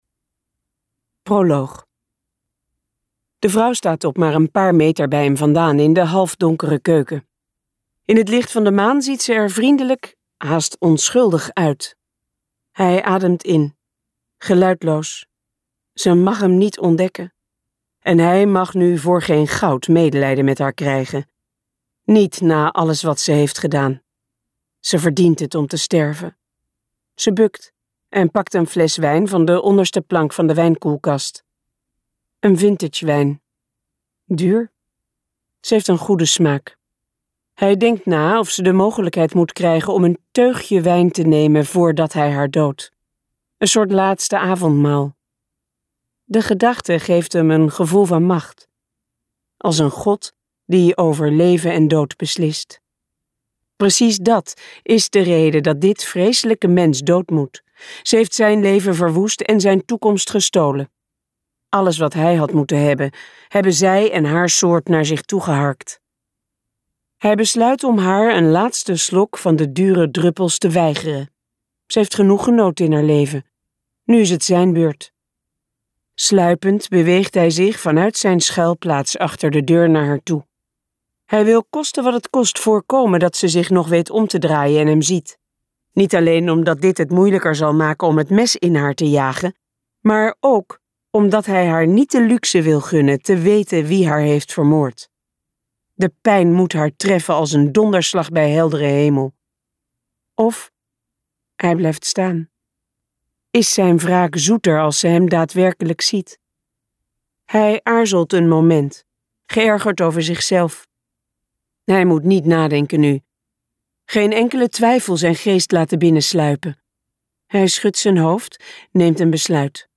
Ambo|Anthos uitgevers - Onder de felle zon luisterboek